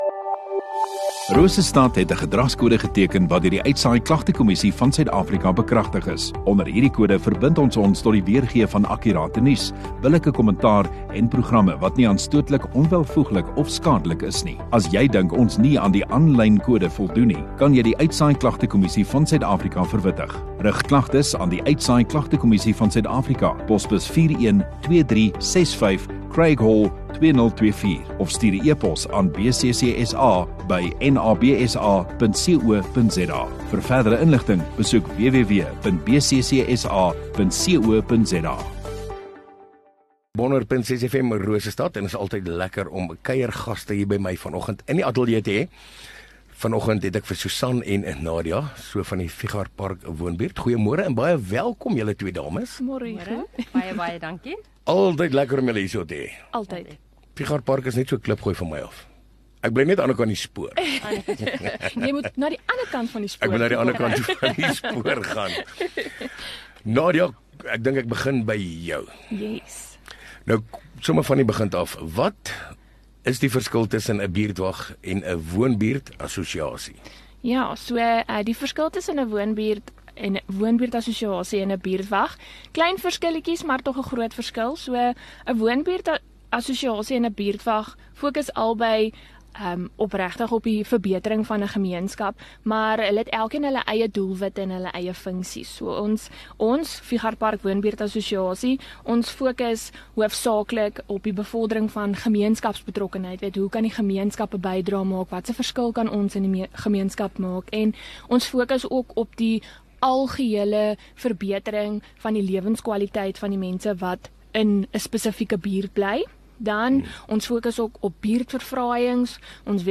View Promo Continue Radio Rosestad Install Gemeenskap Onderhoude 20 Nov Fichartpark Woonbuurt Assosiasie